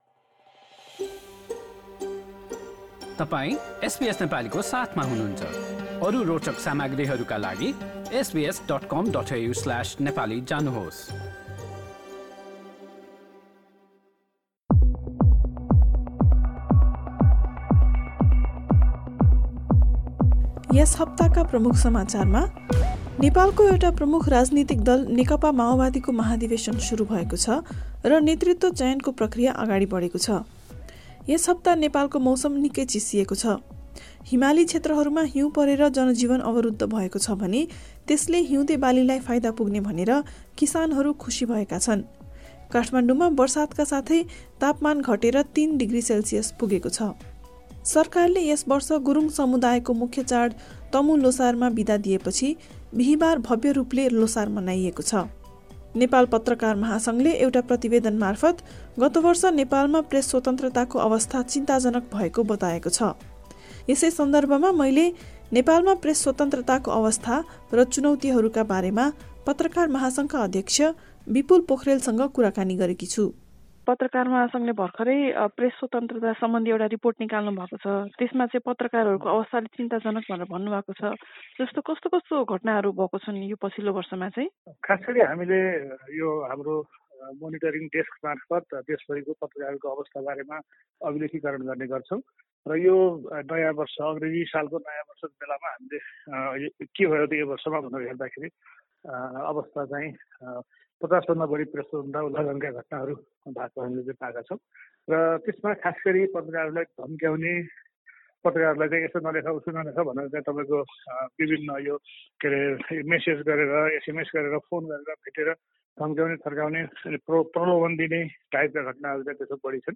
नेपाल पत्रकार महासङ्घको पछिल्लो प्रतिवेदनले सन् २०२१ को एक वर्षको अवधिमा ६२ वटा प्रेस स्वतन्त्रता हननका घटना भएको देखिएको छ भने यसबाट १९० जना पत्रकार ४ सञ्चार माध्यम पीडित भएका छन्। पछिल्ला सात दिनका समाचारका साथ यस हप्ताको विशेष कुराकानी सुन्नुहोस्।